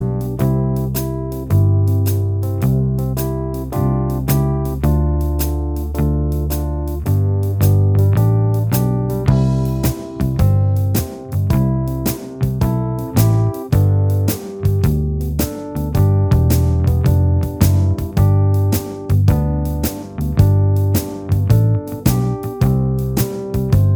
Minus Guitars Pop (1970s) 3:29 Buy £1.50